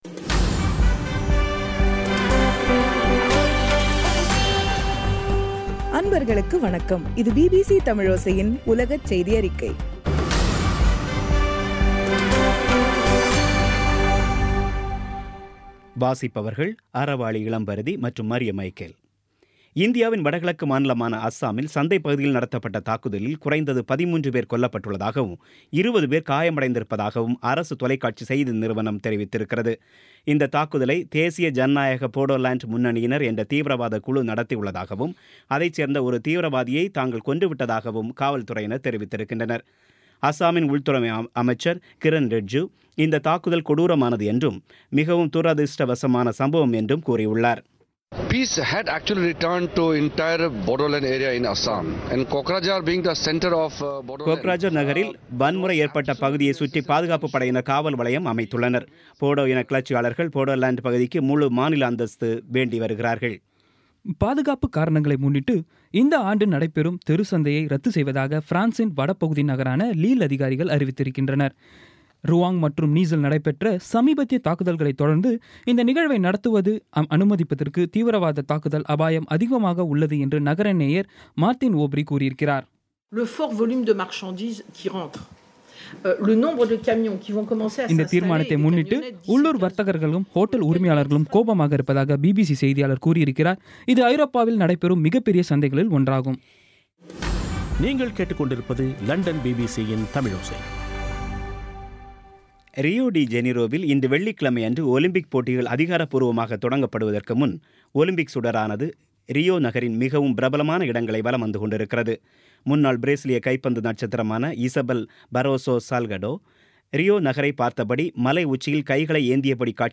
இன்றைய (ஆகஸ்ட் 5ம் தேதி ) பிபிசி தமிழோசை செய்தியறிக்கை